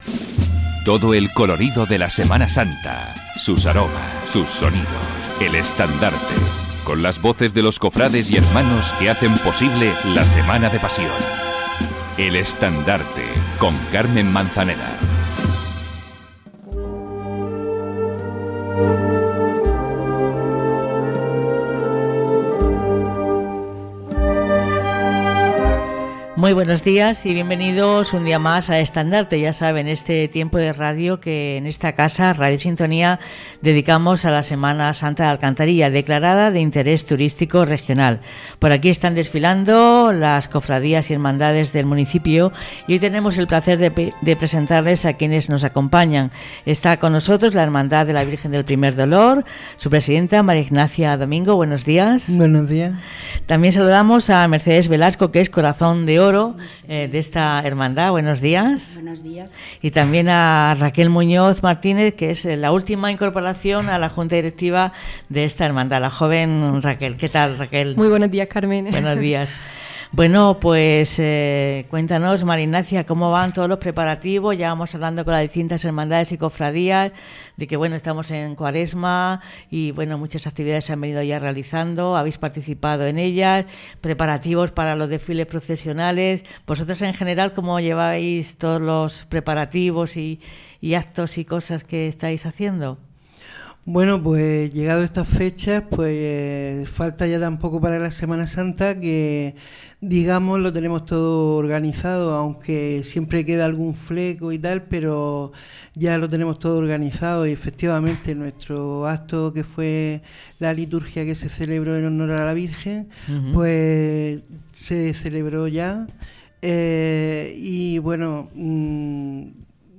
En la entrevista hemos descubierto una hermandad profundamente arraigada en la tradición, la fe y la comunidad de Alcantarilla. Se destaca la dedicación de sus miembros, la incorporación de nuevas generaciones, el respeto por sus costumbres y la intensa emoción que vivencian durante la Semana Santa.